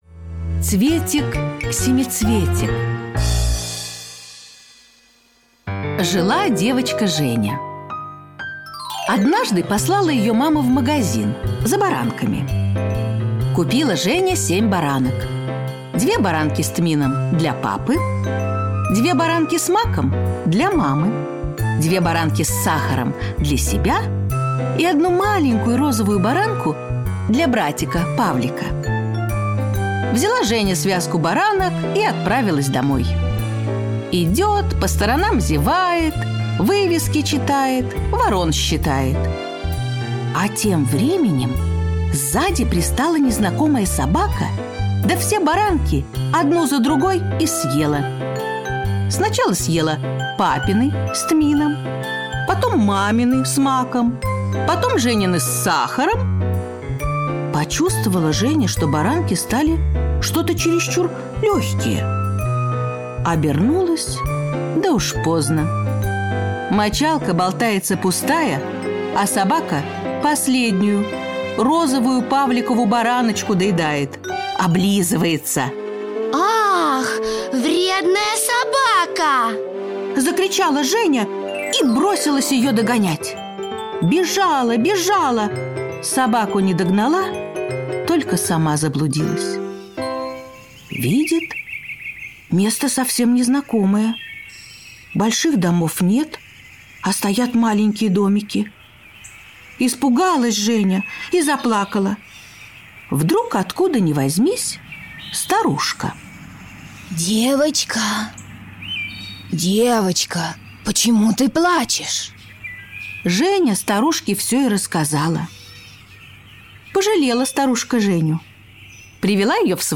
Слушайте Цветик-семицветик - аудиосказка Катаева В.П. Сказка про девочку Женю, которой подарили волшебный цветок, исполнявший желания.